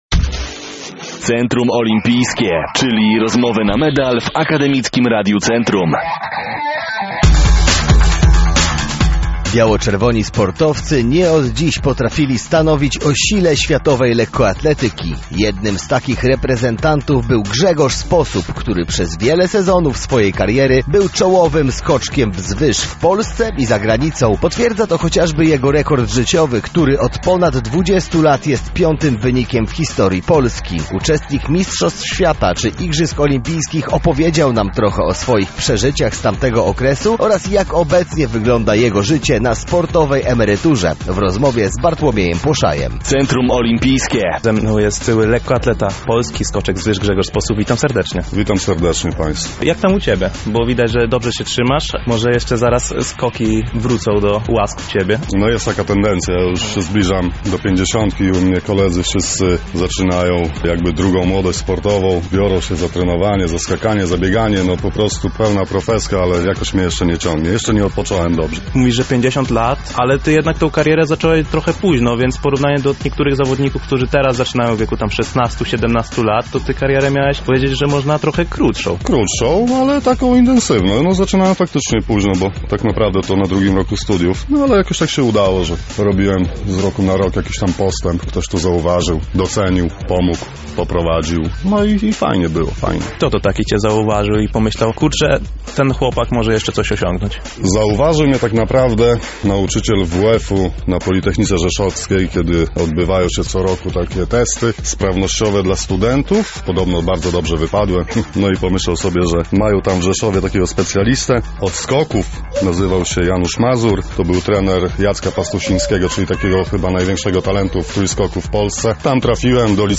Tym razem mieliśmy okazję porozmawiać z jednym z najwybitniejszych skoczków wzwyż – Grzegorzem Sposobem.